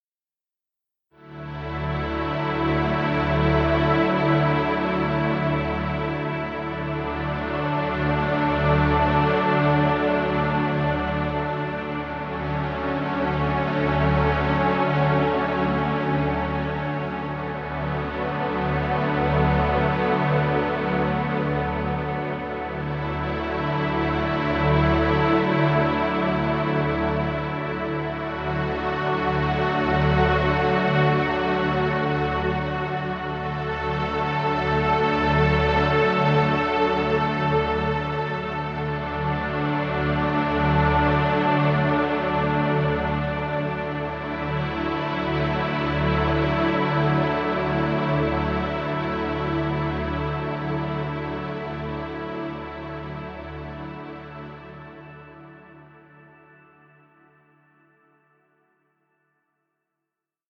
Relaxing music.
Background Music.